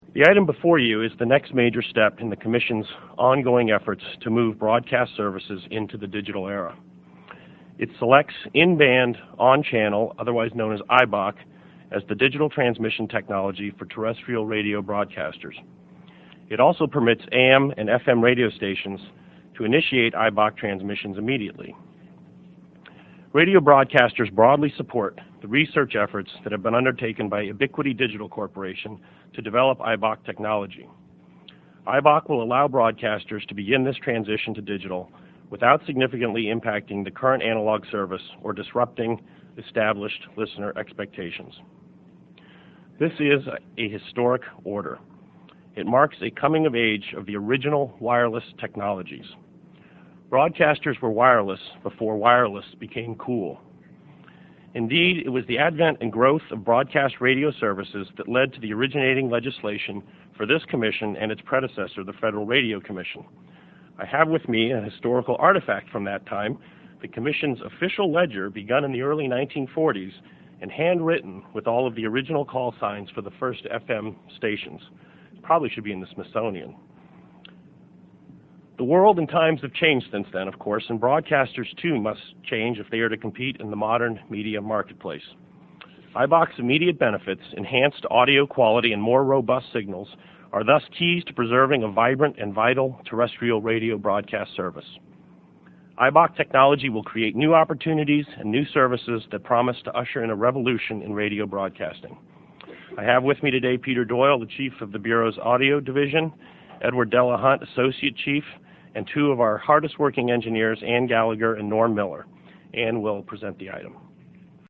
Note: All audio is in 48kbps/44KHz mono MP3 format.
W. Kenneth Ferree - Chief, FCC Media Bureau (1:54, 673K)